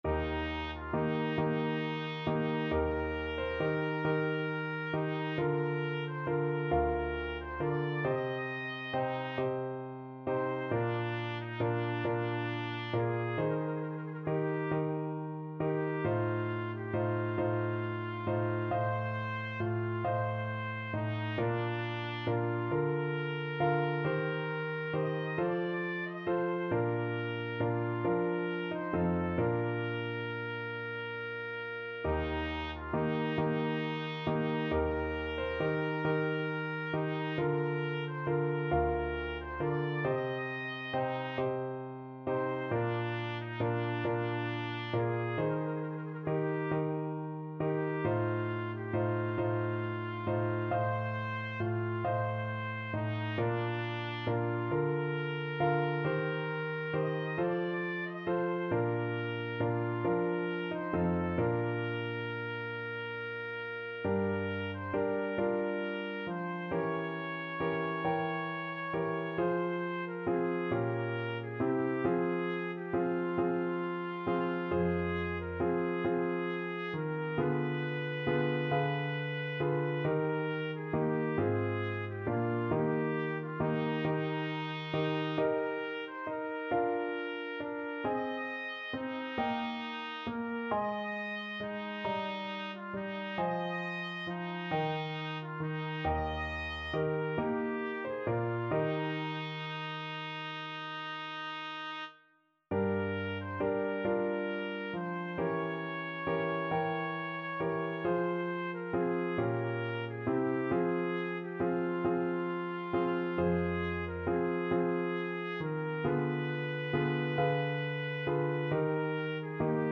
Classical Vivaldi, Antonio Concerto for Flute and String Orchestra 'Il Gardellino', Op.10, No.3, 2nd movement Trumpet version
Trumpet
12/8 (View more 12/8 Music)
II: Larghetto cantabile .=45
Eb major (Sounding Pitch) F major (Trumpet in Bb) (View more Eb major Music for Trumpet )
Classical (View more Classical Trumpet Music)